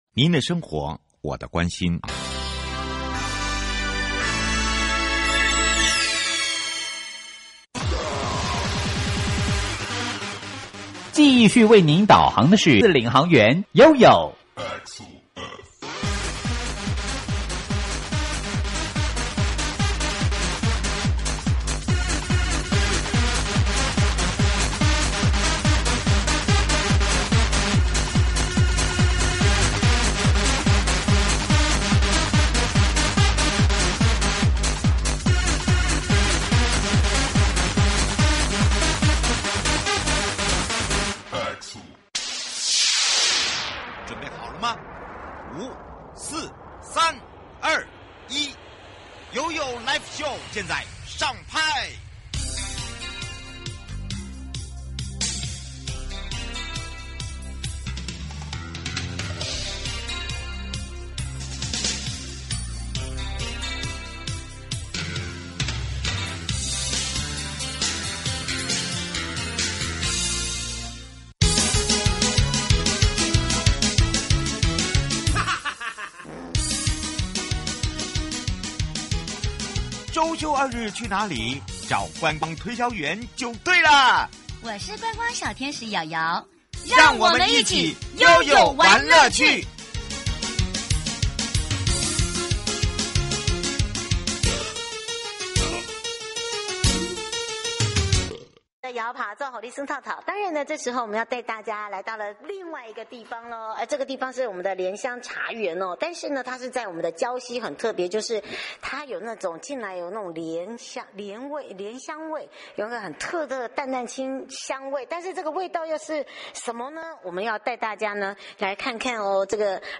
【聯全麻糬手作概念館】是在宜蘭一家專門對外營業的麻糬伴手禮店。 受訪者：